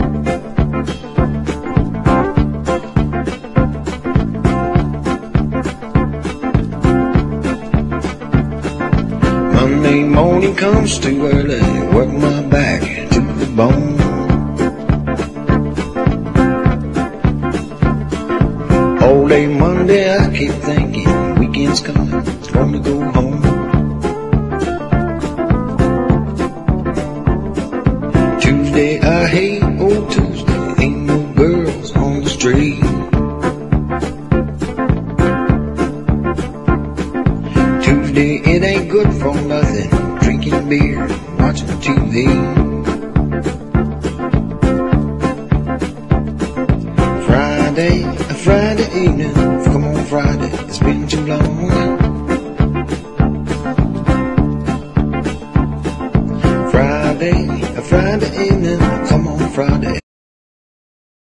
JAPANESE / 90'S～ (JPN)
熱くタイトな演奏でファンキー・グルーヴがガツンと弾ける、勢い十分のハイ・テンションなサウンド！